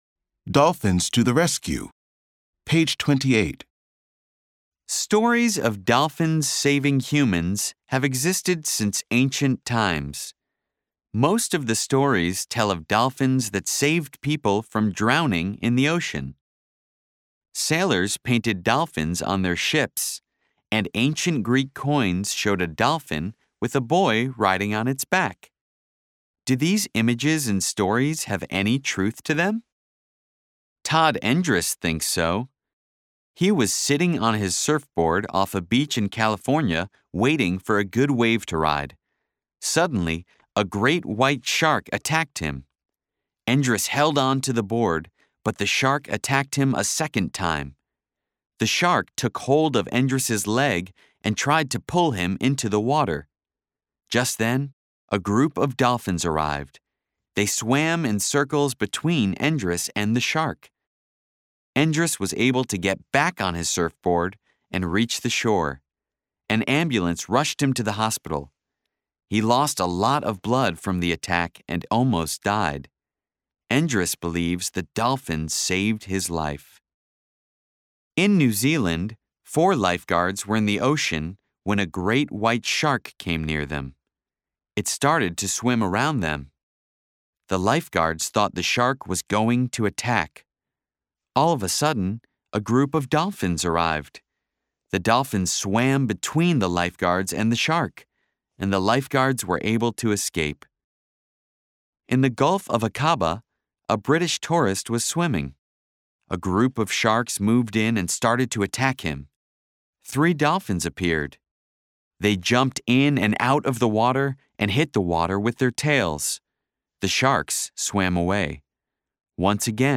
Bound into the back of the book is an audio CD that contains audio recordings of all the stories in the Student's Book.